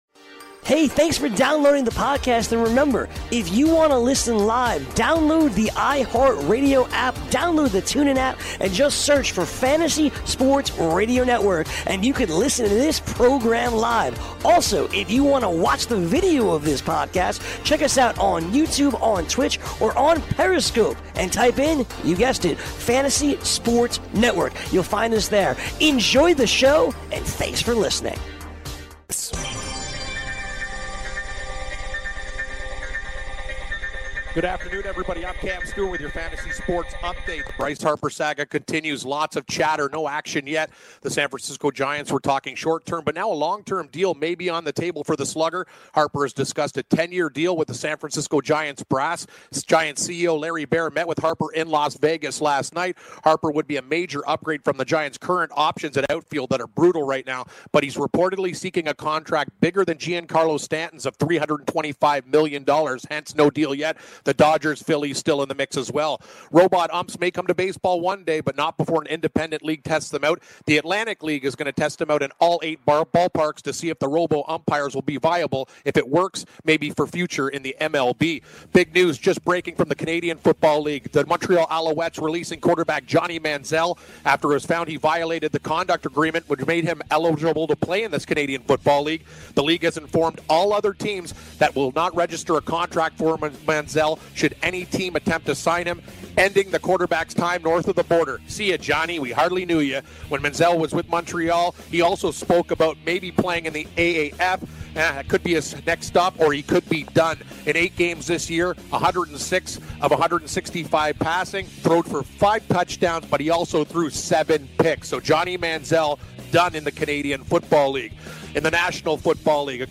Jim Leyland Interview replay